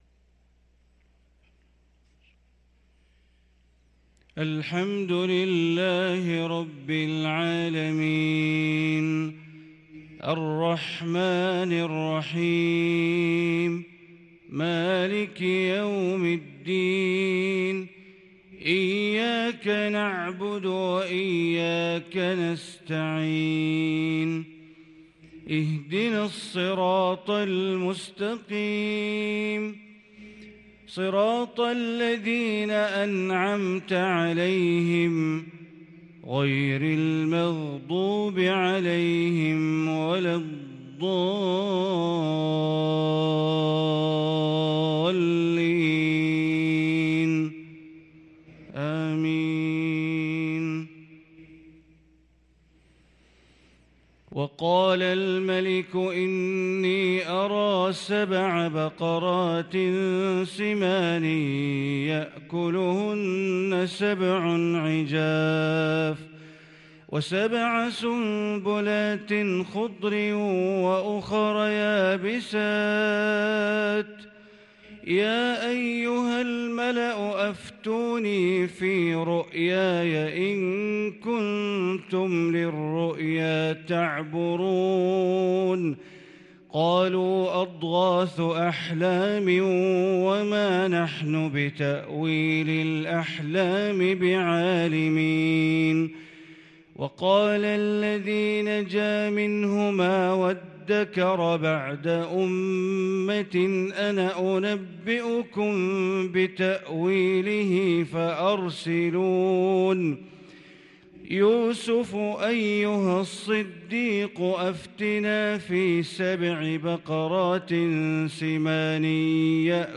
صلاة الفجر للقارئ بندر بليلة 15 ربيع الأول 1444 هـ